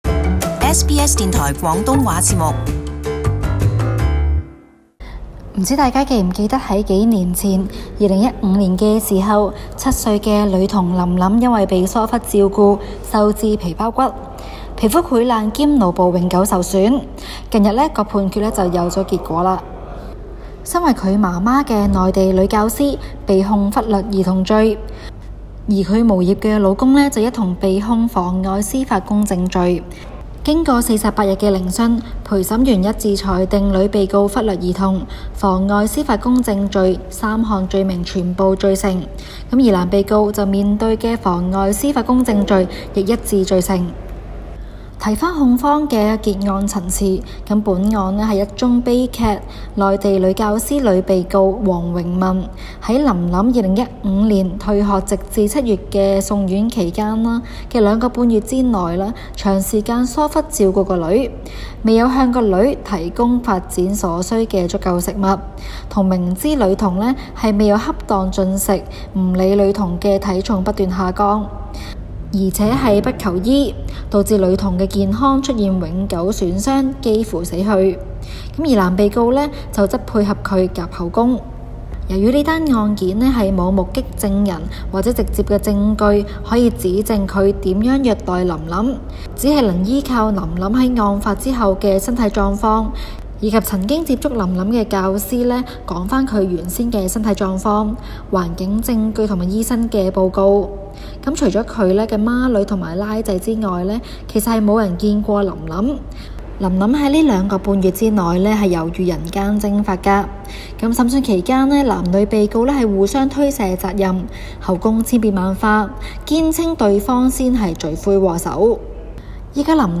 【中港快訊】七歲女童被虐變植物人 父母罪成